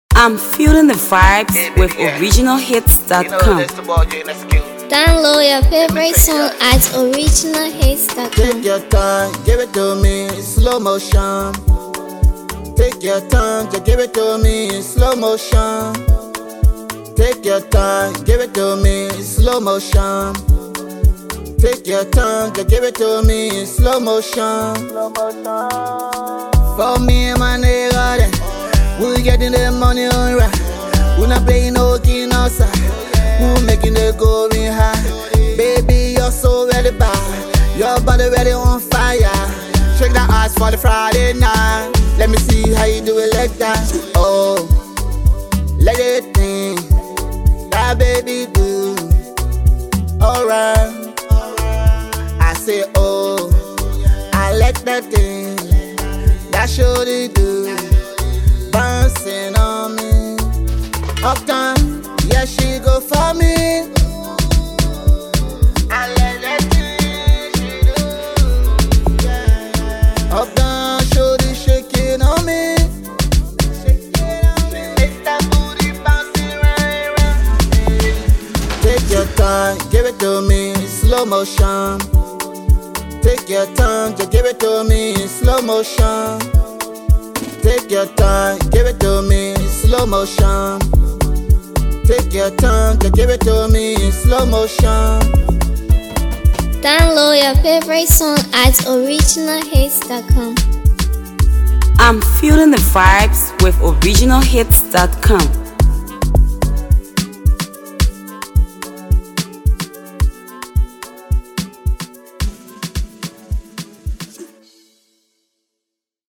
Brand new banger from talented Liberian artist